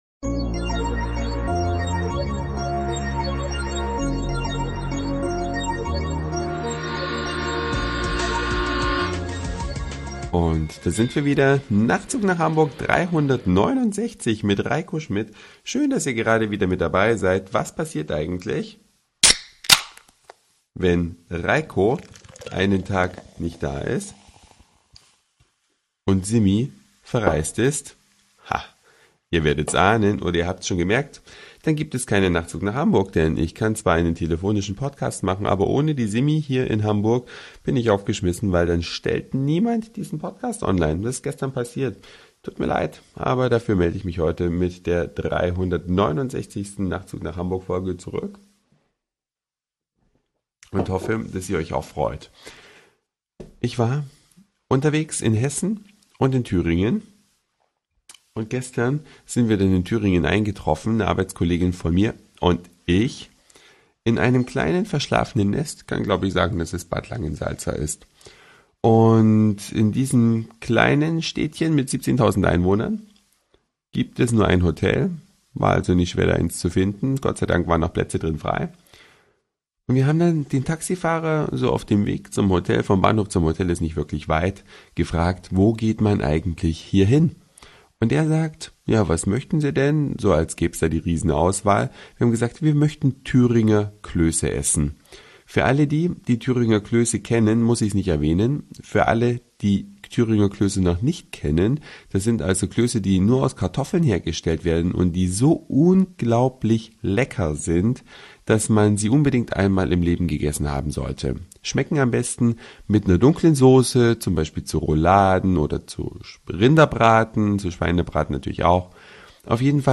Was mit einem telefonischen Podcast passiert,